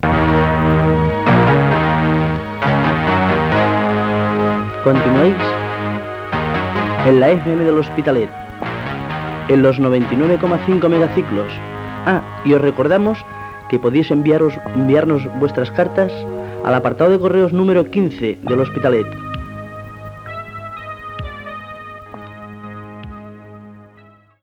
Identificació de l'emissora i adreça postal.
FM